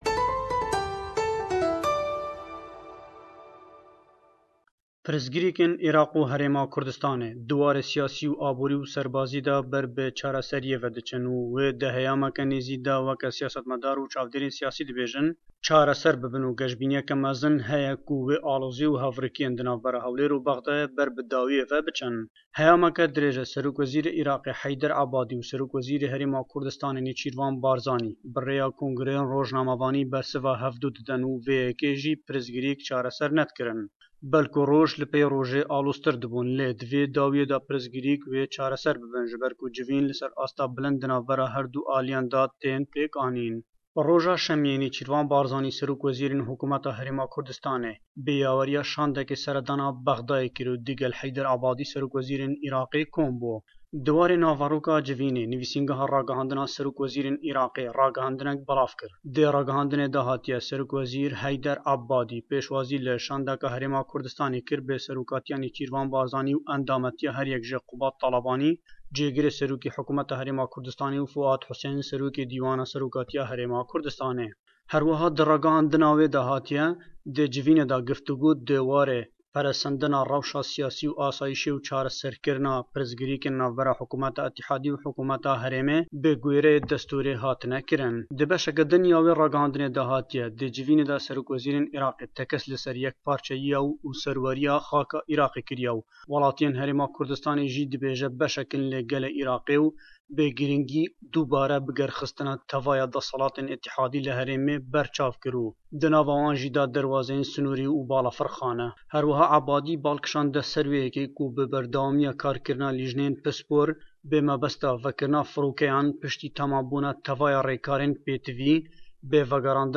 Peyamnêrman le Hewlêr e we,